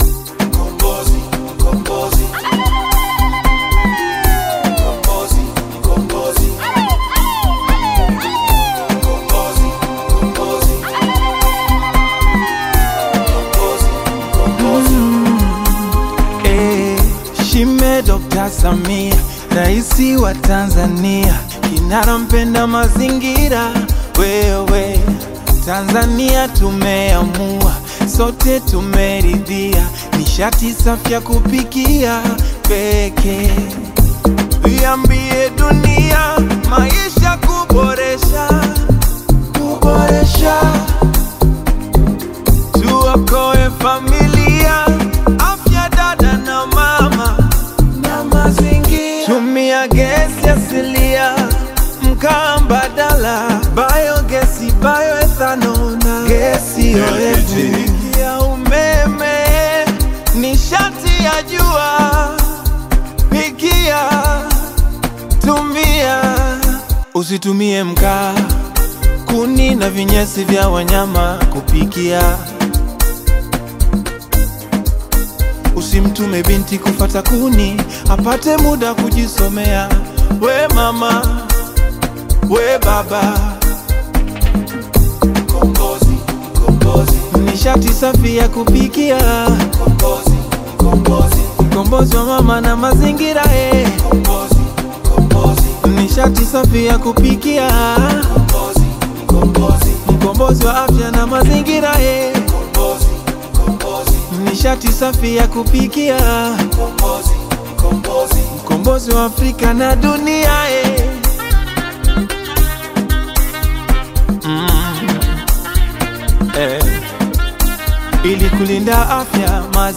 soulful Afro-House/Bongo Flava single
Genre: Bongo Flava